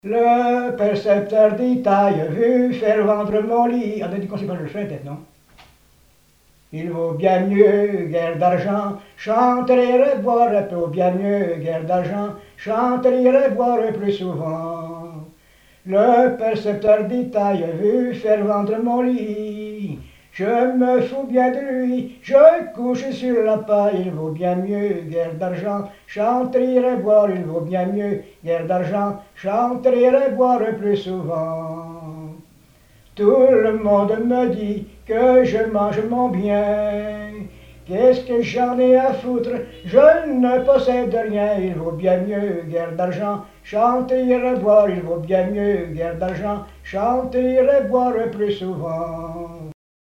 Bouchoux (Les)
Genre strophique
chansons et témoignages parlés
Pièce musicale inédite